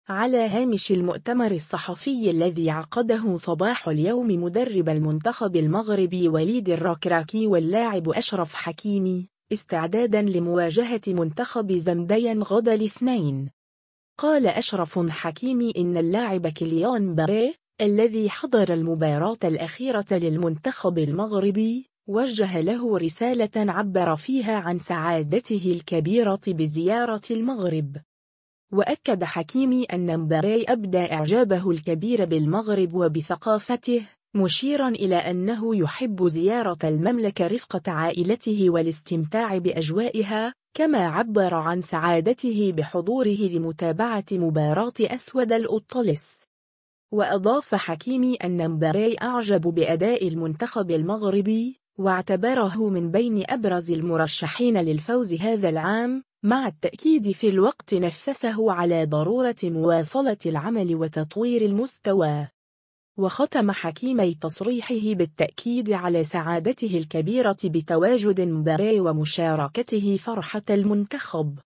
على هامش المؤتمر الصحفي الذي عقده صباح اليوم مدرب المنتخب المغربي وليد الركراكي واللاعب أشرف حكيمي، استعدادًا لمواجهة منتخب زمبيا غدًا الاثنين،